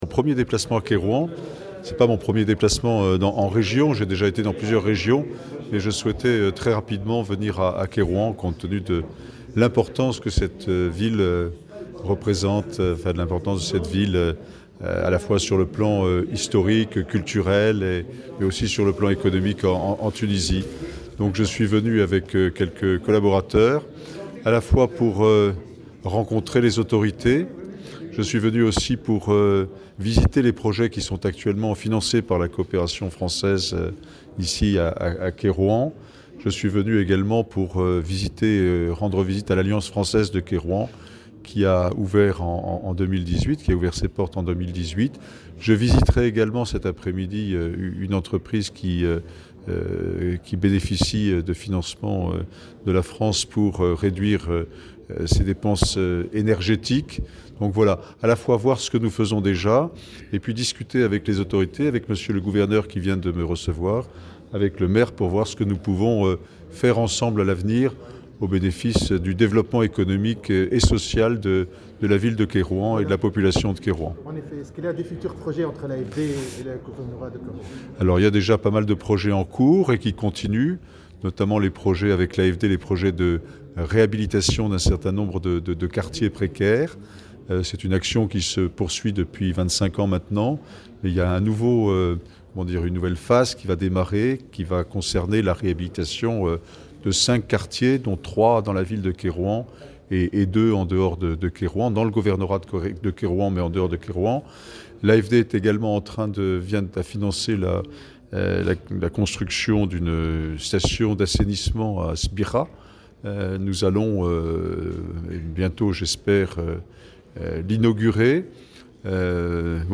وقد تم التطرق خلال اللقاء الى اهم المشاريع الكبرى المبرمجة بالجهة الى جانب تقديم تقرير حول اهم الخصائص والمميزات الحضارية والجغرافية والاقتصادية لولاية القيروان وابرز المشاغل التنموية وسبل تطوير التعاون الثنائي بمختلف المجالات الى جانب متابعة مشاريع الوكالة الفرنسية للتنمية بالجهة . وفي تصريح للجوهرة أف أم، أكد السفير الفرنسي، أن هذه الزيارة تندرج في إطار سلسلة من الزيارات التي أداها لعدد من جهات البلاد وقادته اليوم إلى ولاية القيروان بما تمثله من وزن اقتصادي وتاريخي في تونس.